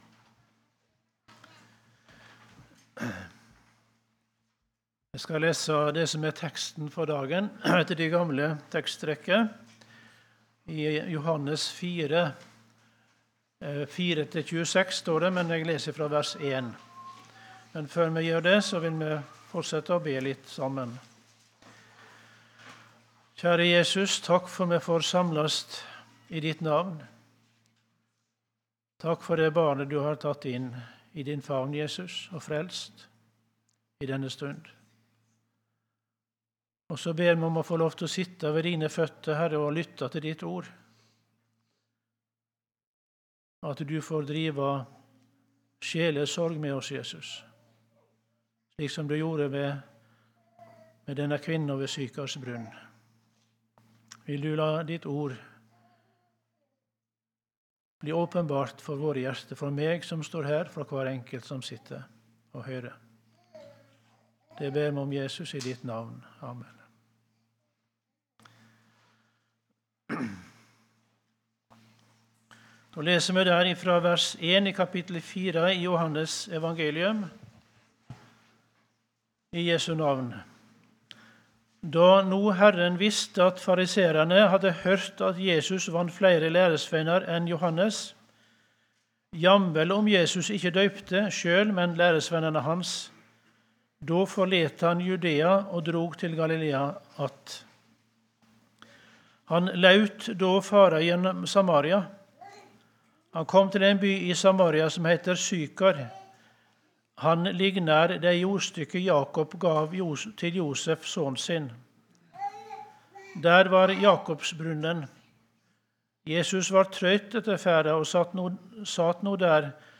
18.01.2026 | Søndagsmøte på Fossnes